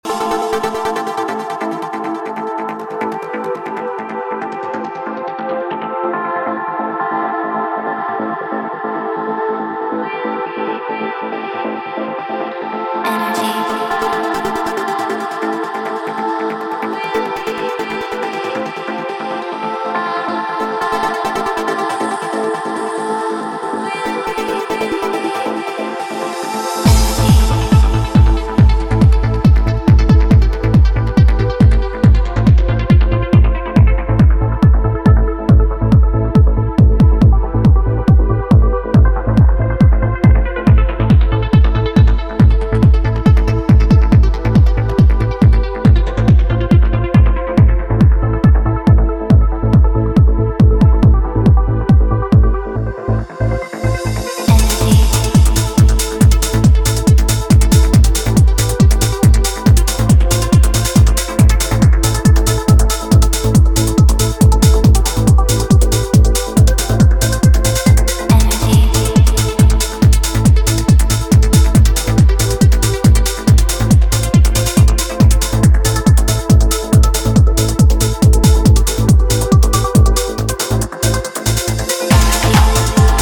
With its deep and captivating melodies
Trance and Progressive House
soaring melodies, spacey leads, and groovy percussion.